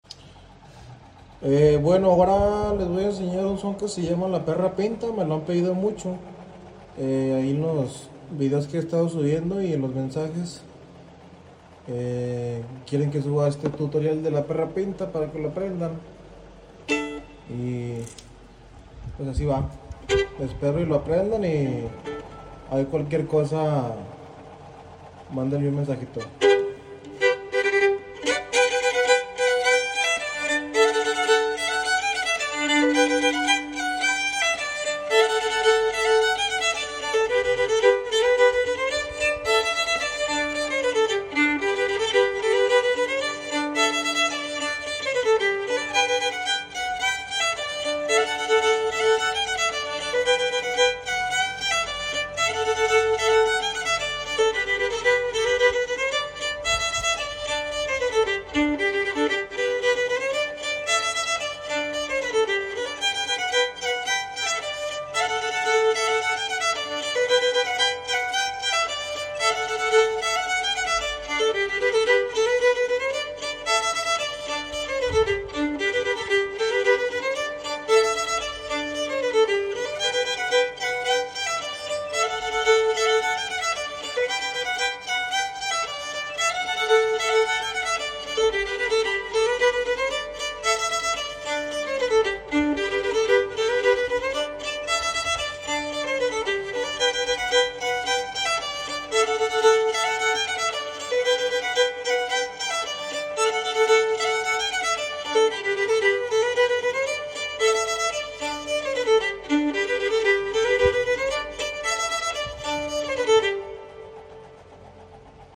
APRENDE A TOCAR SONES DE DANZA EN VIOLÍN.